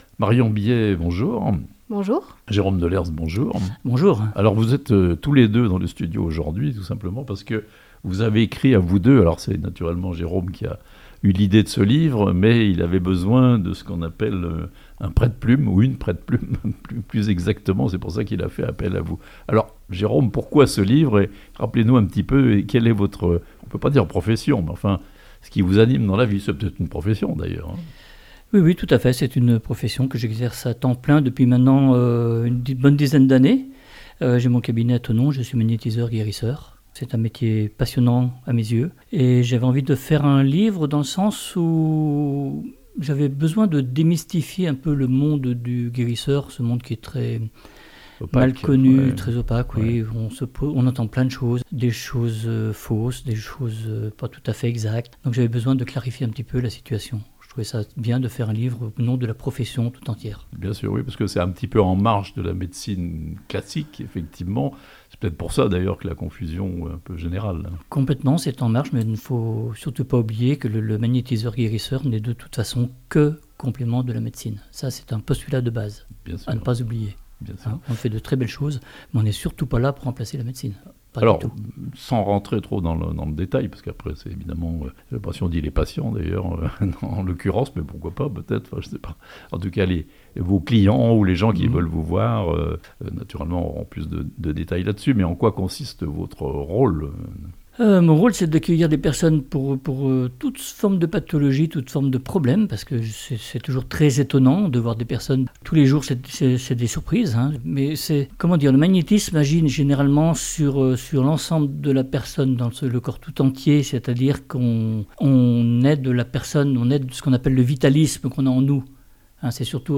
Un livre pour tenter de répondre aux questions que l'on se pose sur la profession de magnétiseur (interviews)